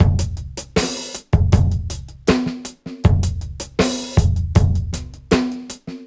Index of /musicradar/sampled-funk-soul-samples/79bpm/Beats
SSF_DrumsProc1_79-01.wav